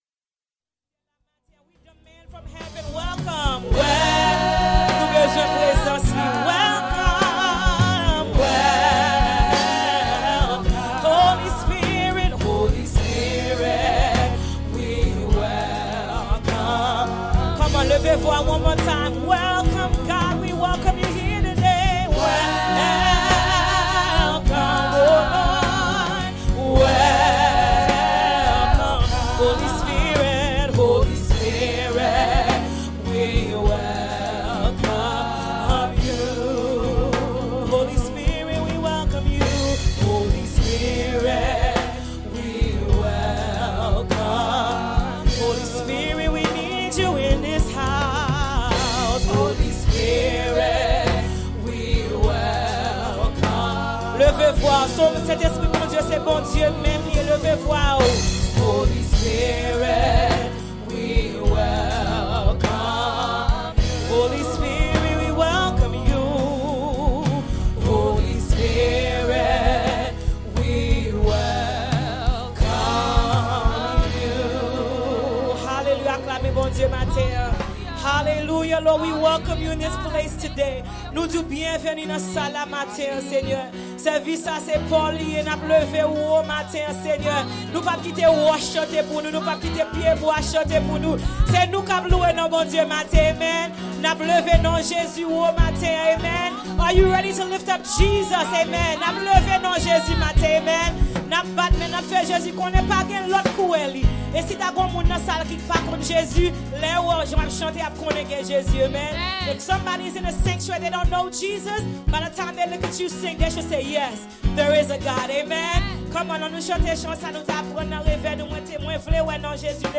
– Welcome to Eben-Ezer C&MA Church